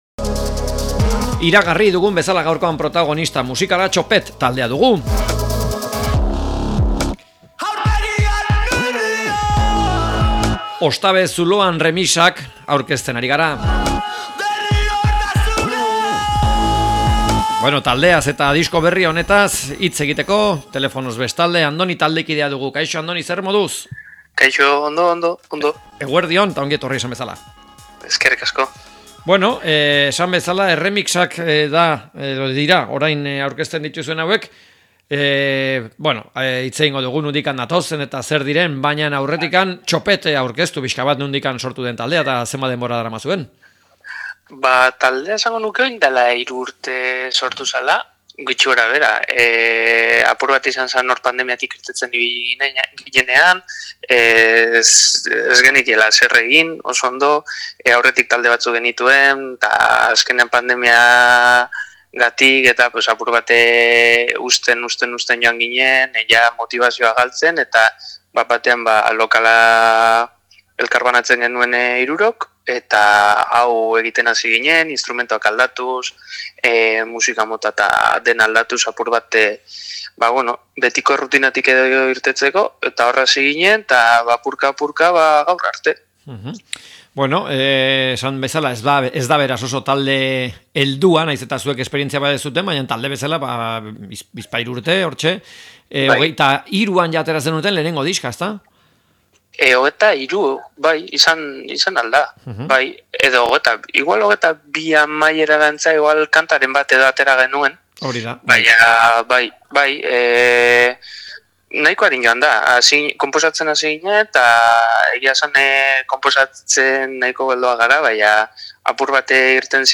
Txopet taldeari elkarrizketa